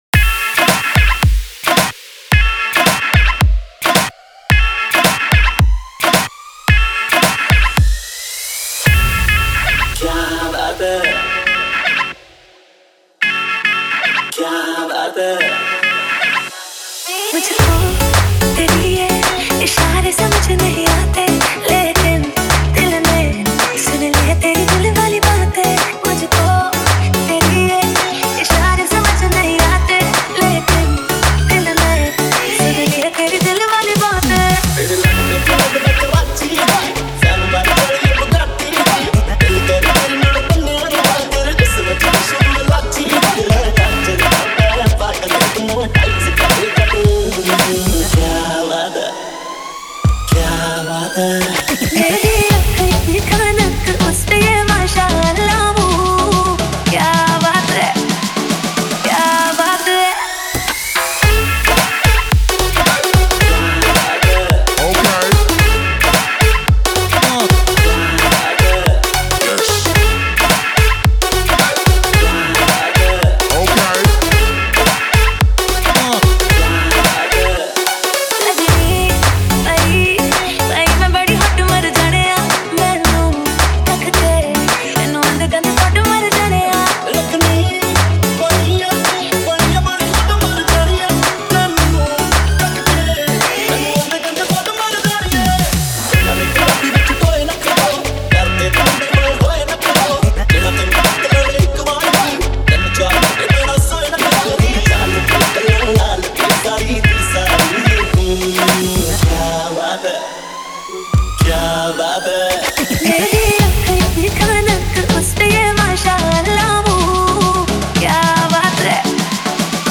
DJ Remix
Bollywood DJ Remix Songs